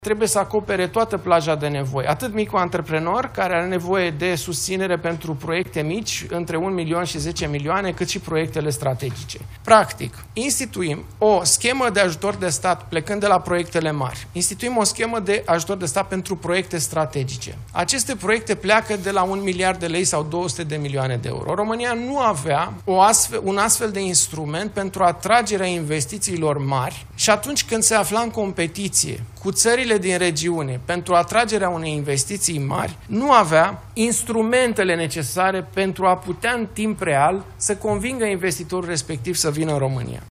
„Pachetul de relansare economică” a fost prezentat joi, 5 februarie, la Palatul Victoria.
Ministrul Finanțelor, Alexandru Nazare: „România nu avea un astfel de instrument pentru atragerea investițiilor mari”